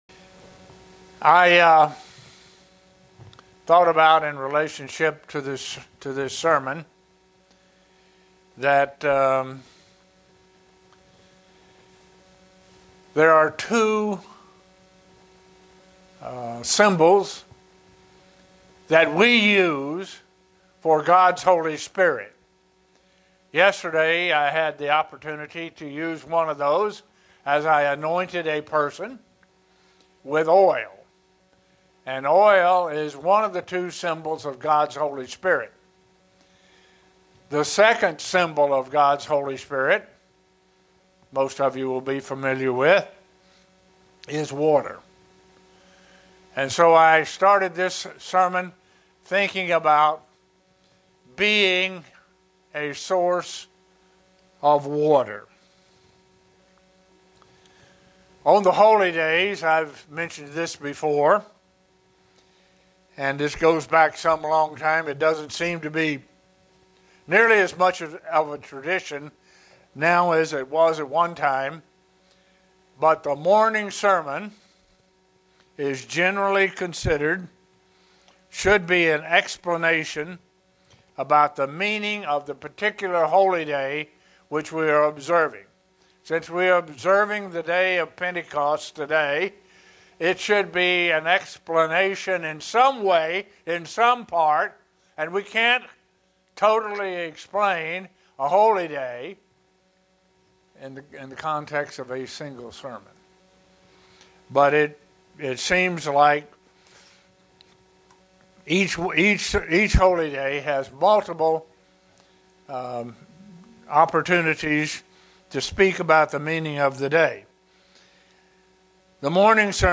Sermons
Given in Buffalo, NY Elmira, NY